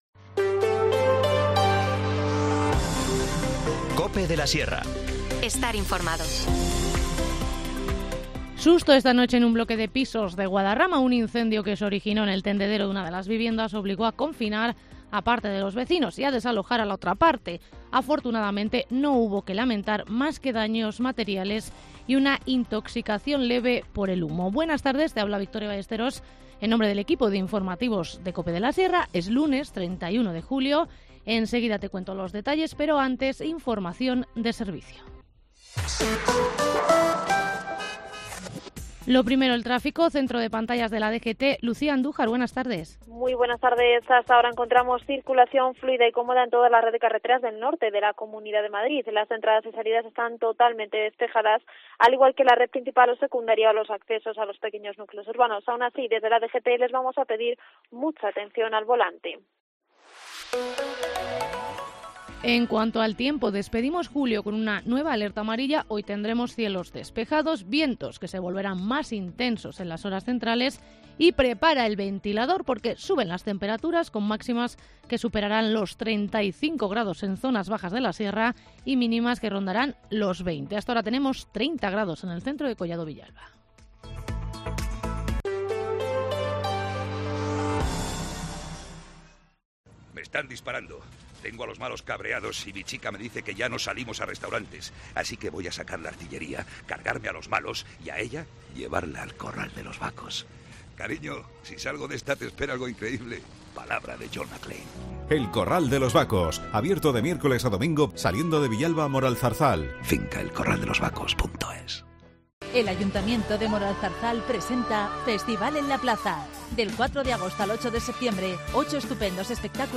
Informativo Mediodía 31 julio